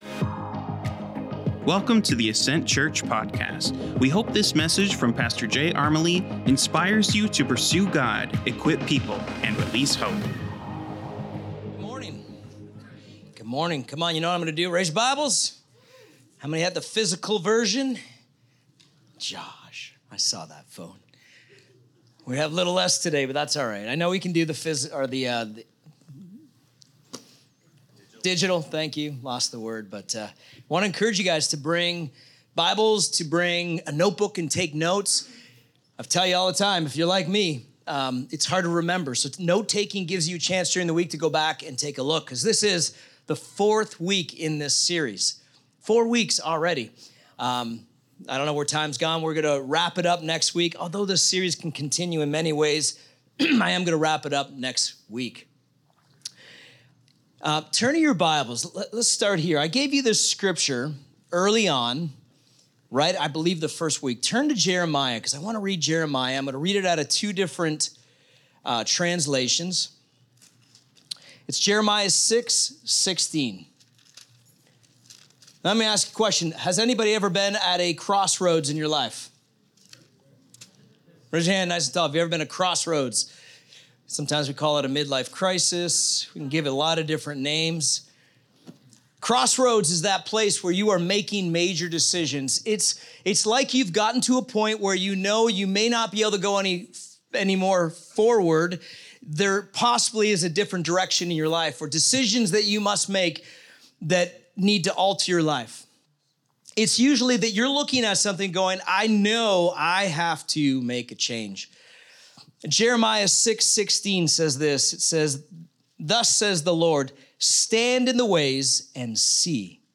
Current Weekly Sermon